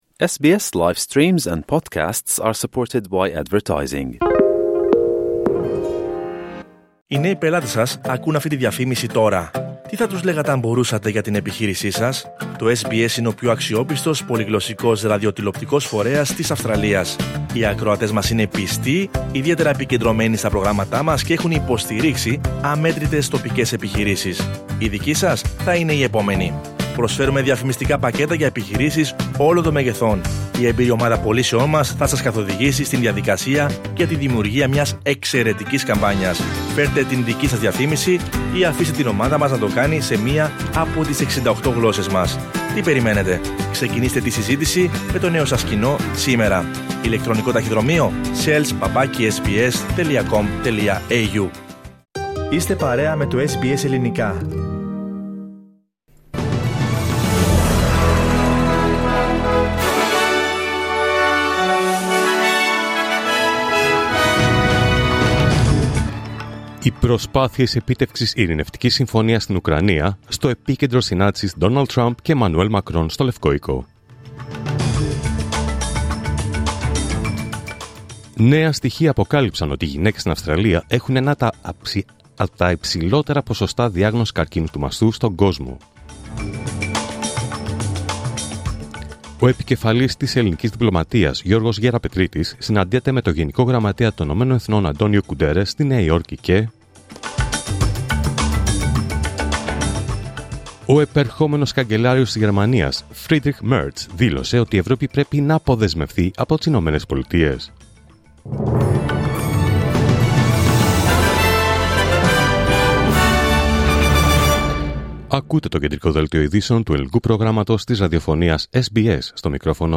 Δελτίο Ειδήσεων Τρίτη 25 Φεβρουάριου 2025